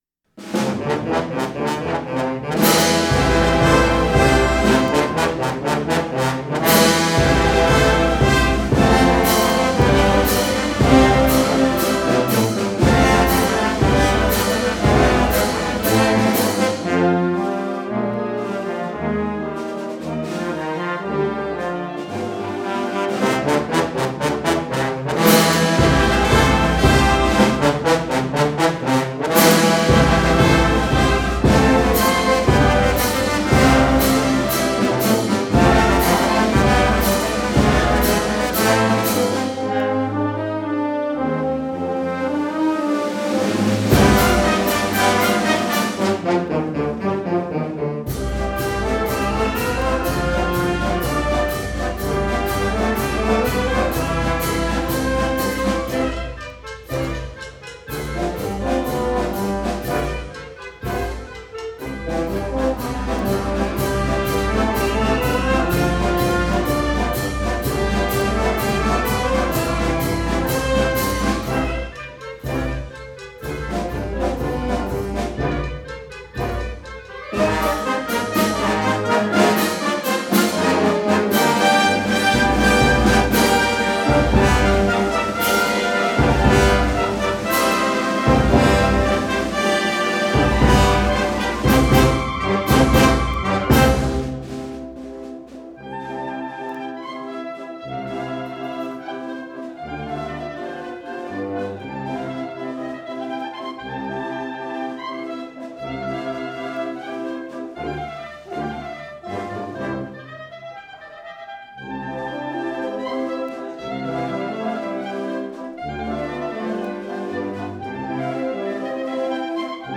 marcia per banda musicale